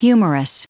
Prononciation du mot humorous en anglais (fichier audio)
Prononciation du mot : humorous